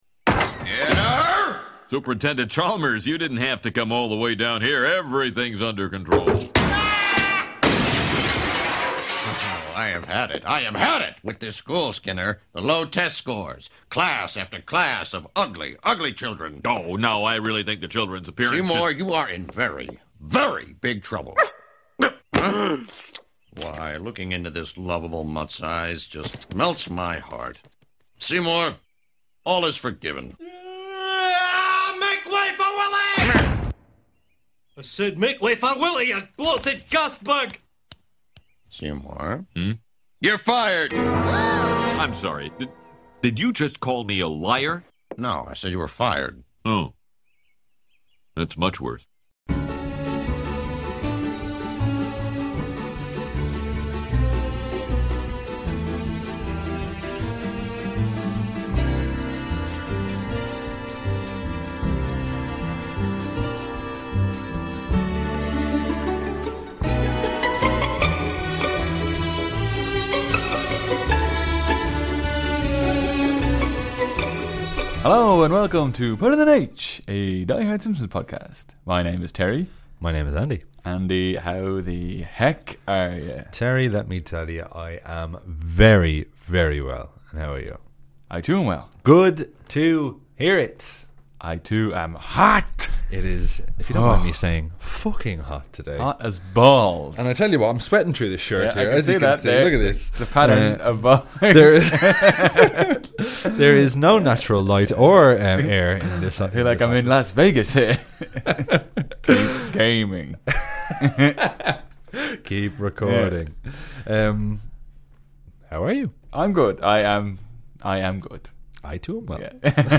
ALL while being couped up in an insanely hot studio.